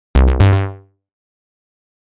Scifi 12.mp3